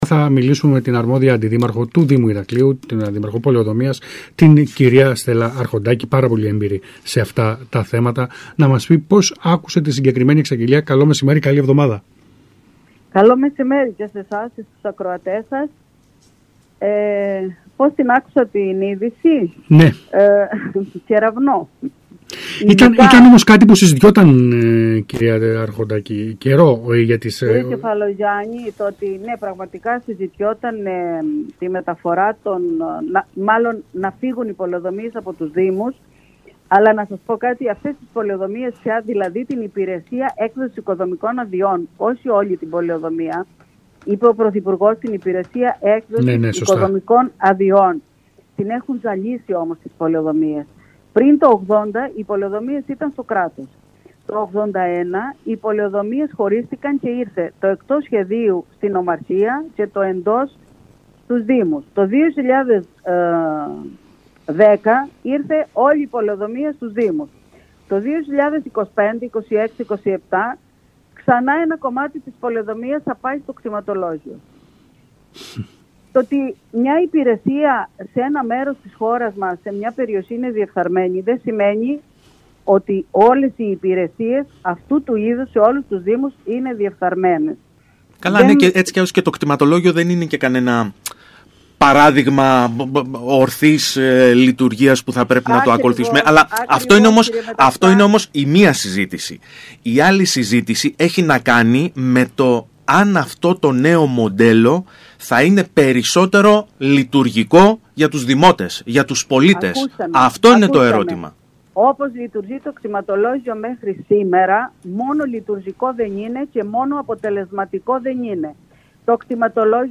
Ακούστε εδώ όσα είπε η Αντιδήμαρχος Πολεοδομίας Στέλα Καλογεράκη – Αρχοντάκη στον ΣΚΑΙ Κρήτης 92.1: